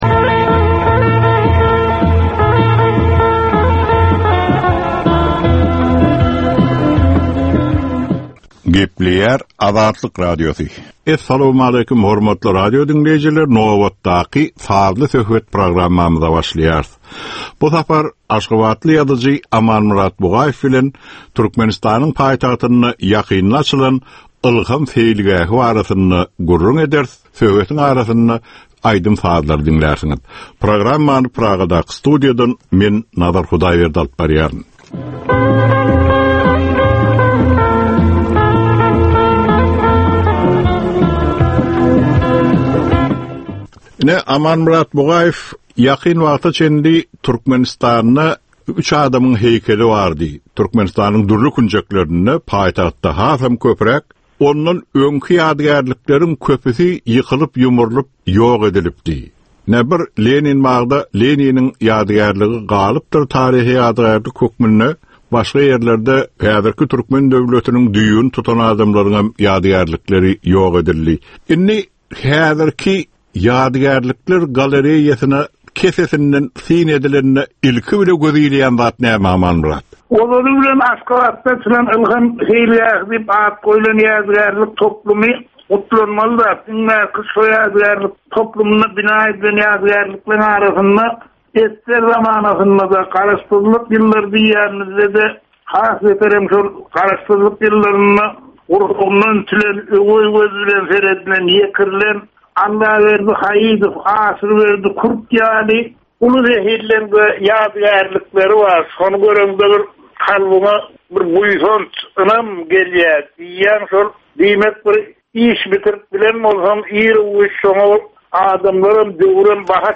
Türkmeniň käbir aktual meseleleri barada sazly-informasion programma.